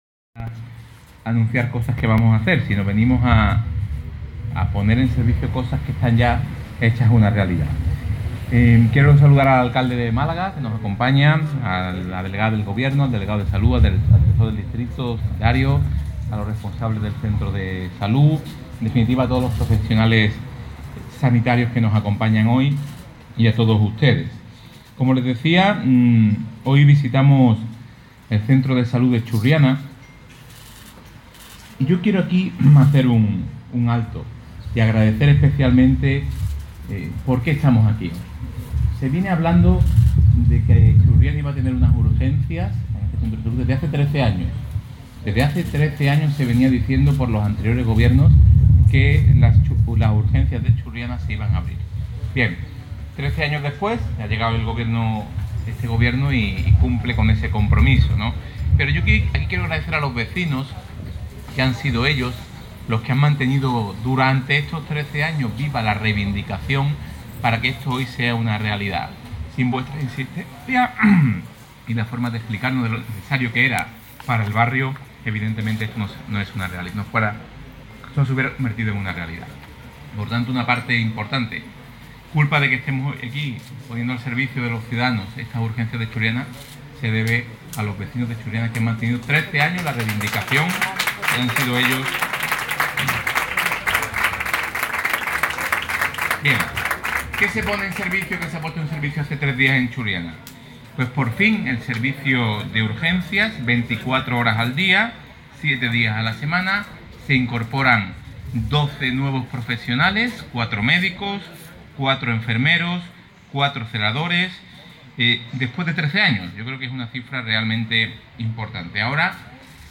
El consejero ha hecho este anuncio durante la inauguración de las nuevas urgencias del centro de salud de Churriana, que comenzaron a funcionar a principio de semana y que permitirán prestar asistencia sanitaria a los vecinos durante las 24 horas del día, los siete días de la semana.